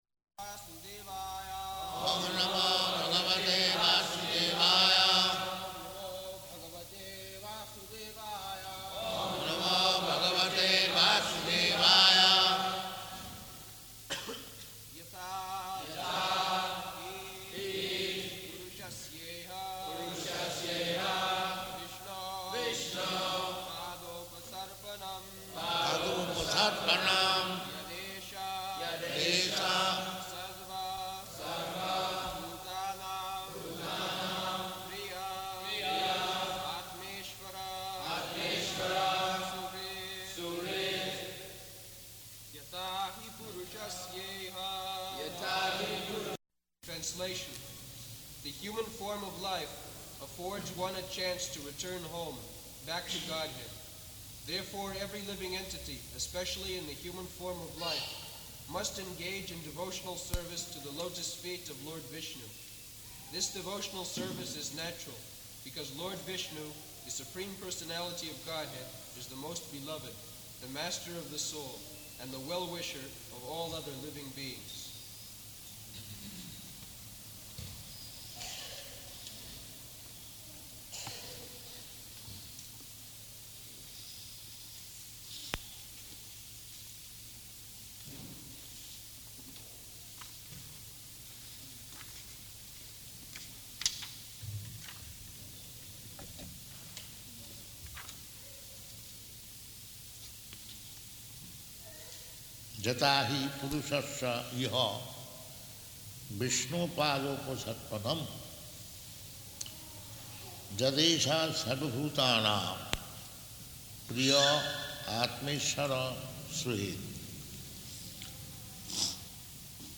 June 18th 1976 Location: Toronto Audio file
[leads devotees in chanting etc.]